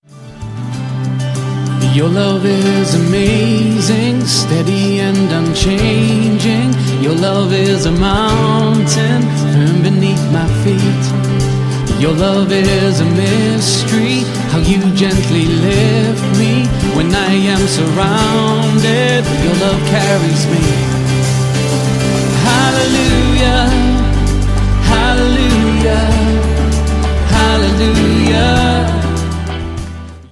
25 modern worship favorites
• Sachgebiet: Praise & Worship